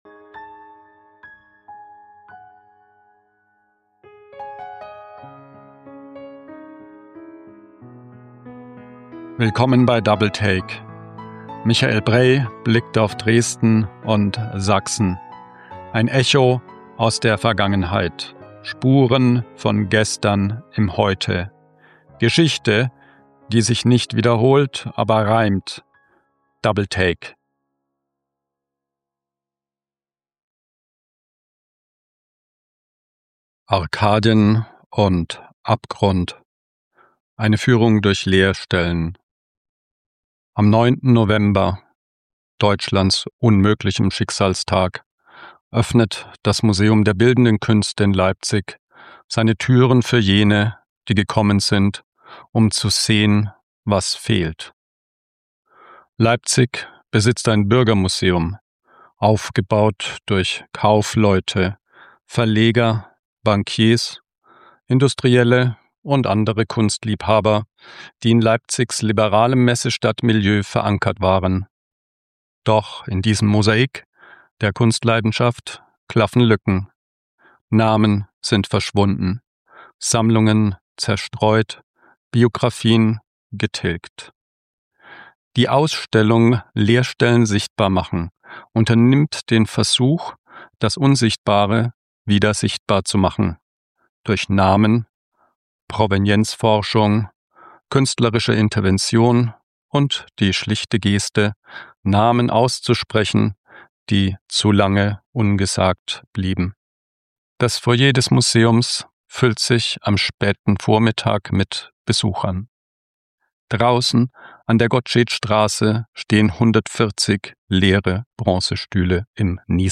Seine Biografie steht stellvertretend für die jüdischen Stifterinnen und Stifter, die das Bürgermuseum mitaufgebaut haben – und aus seinem institutionellen Gedächtnis getilgt wurden. Eine Führung über Provenienzforschung, Restitution und die Frage, die jede Sammlung stellt: Welche Geschichten bewahren wir?